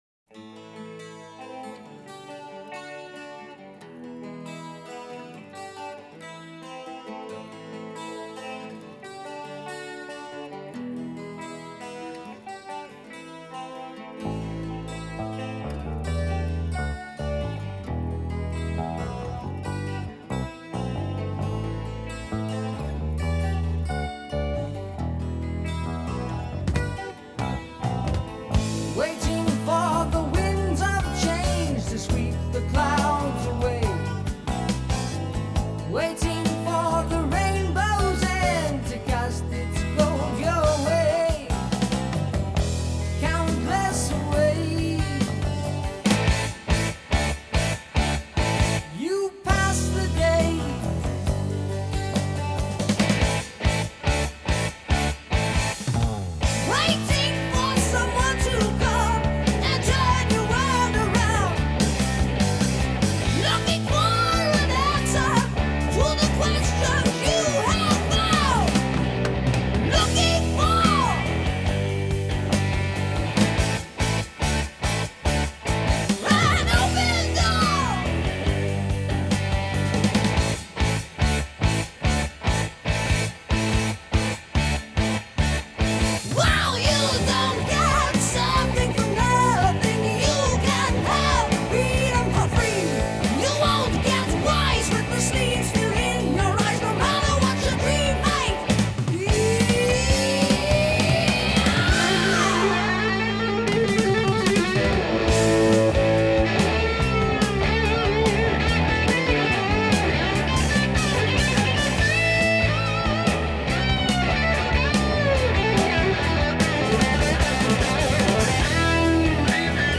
Жанр: Hard Rock, Progressive Rock